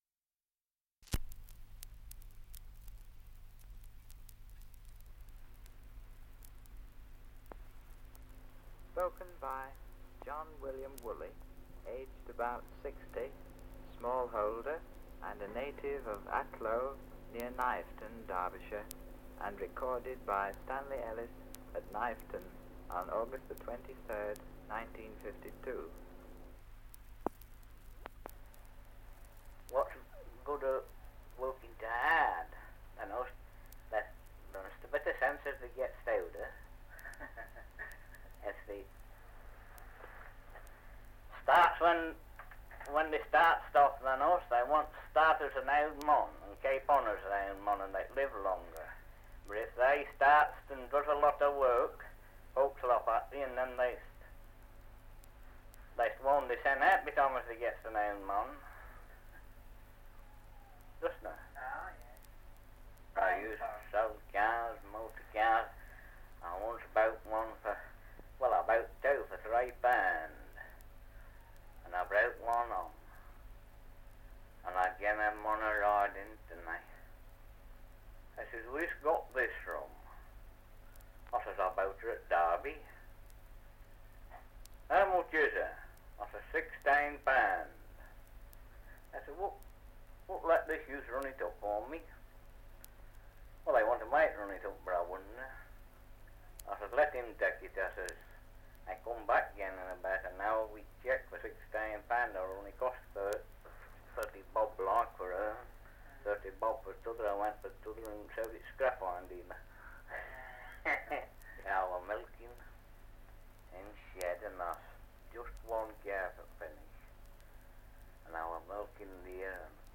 2 - Survey of English Dialects recording in Kniveton, Derbyshire
78 r.p.m., cellulose nitrate on aluminium